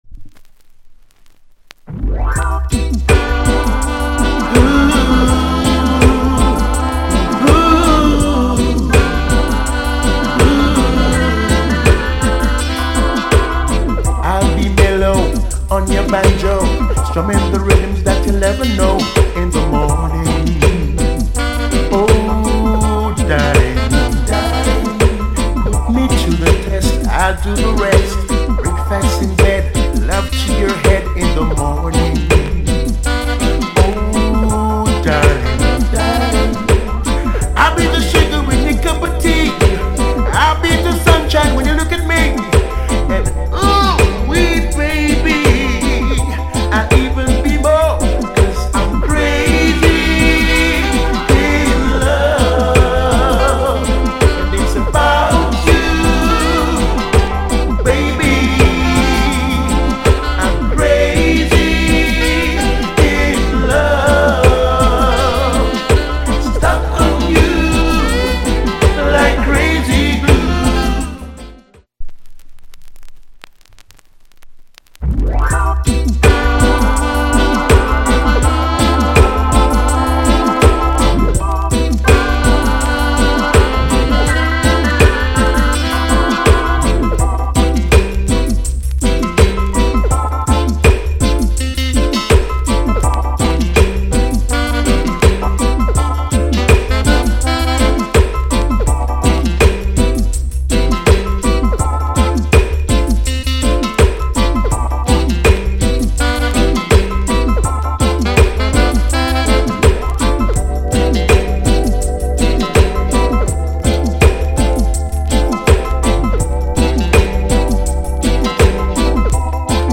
* Jamaican Lovers Rock.